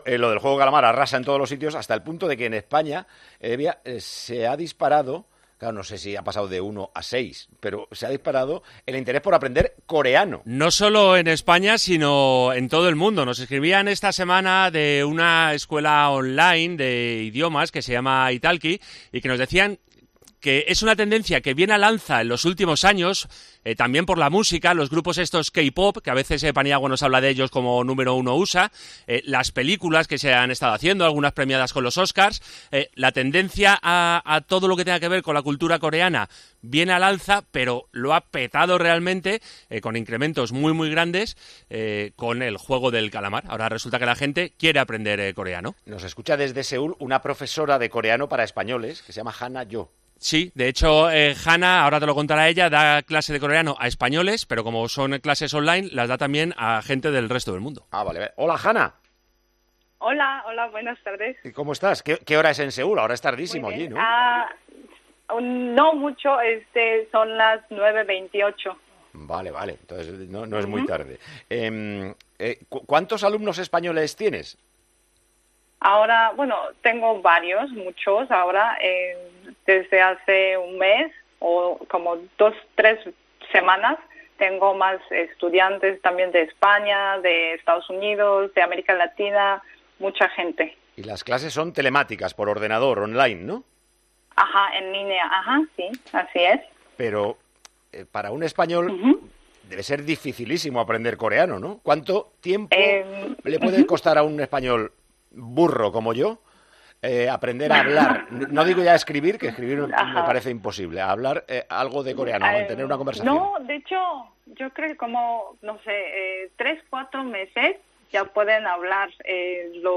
Hablamos con una profesora de coreano para españoles.
Con Paco González, Manolo Lama y Juanma Castaño